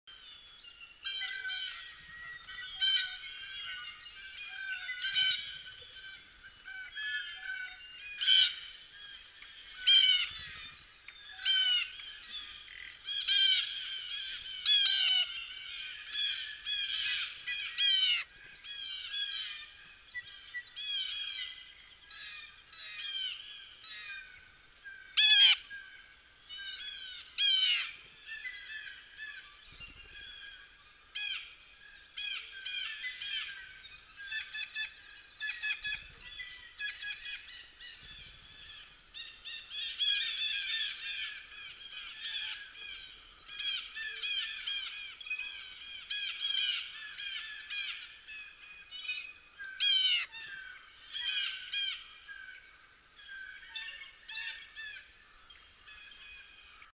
[2005-10-15]【另类】鸟儿的音乐——Nature_Sounds 激动社区，陪你一起慢慢变老！